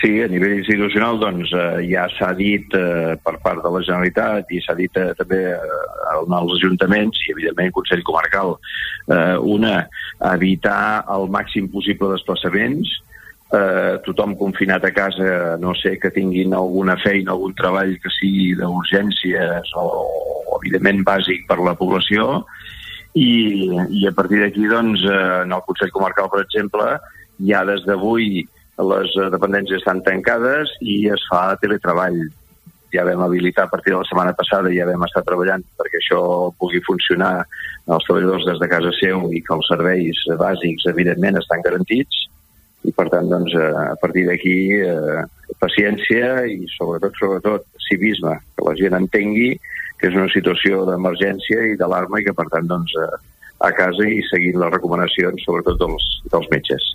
Joan Loureiro, president del Consell Comarcal del Baix Empordà, parlava al Supermatí de les mesures que s'han aplicat a la comarca, seguint els patrons que s'han recomanat en l'àmbit institucional, per fer front al coronavirus